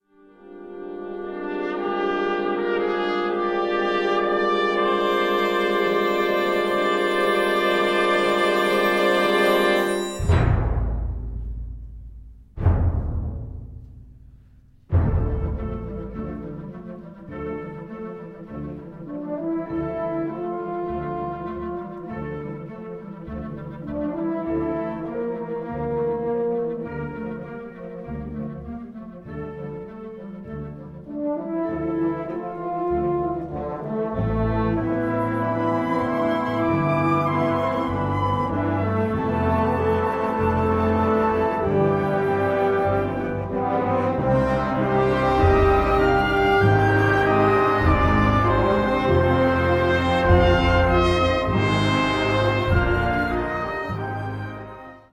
Gattung: Filmmusik
Besetzung: Blasorchester
concert band